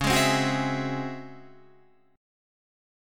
C#mM11 chord